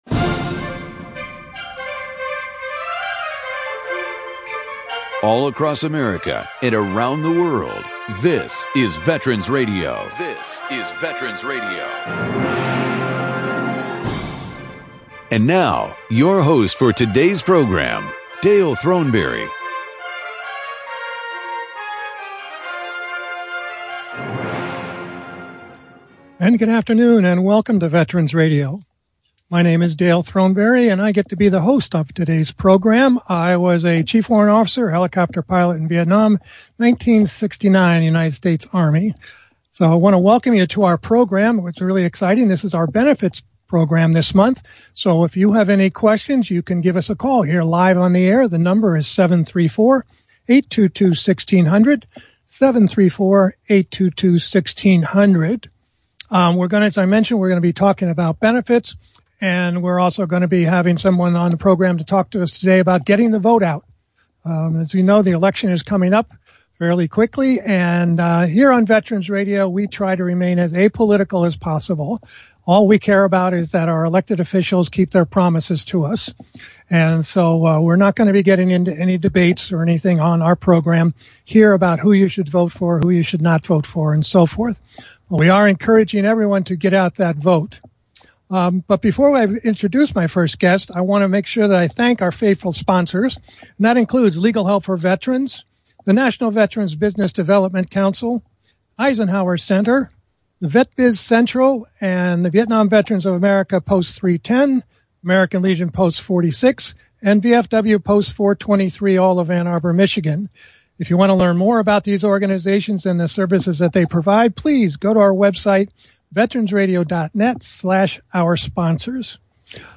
Call in during the program to ask your question or offer a comment.